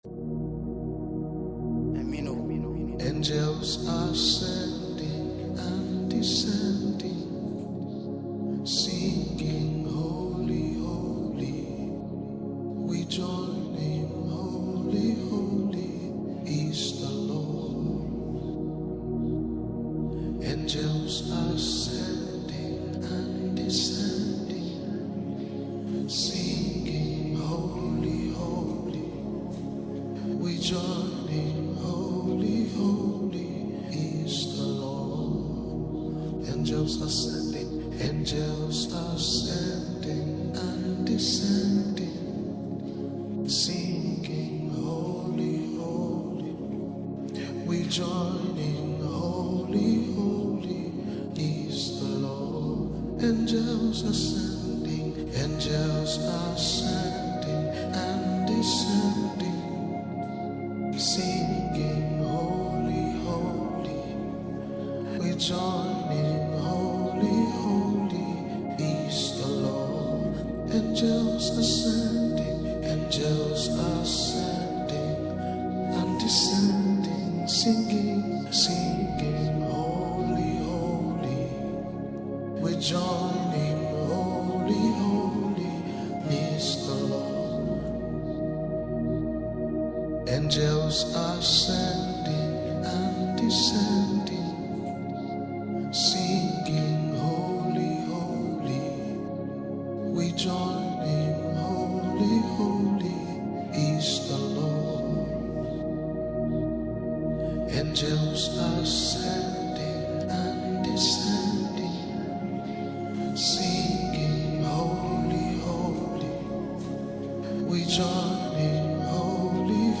a powerful worship song to uplift and inspire you.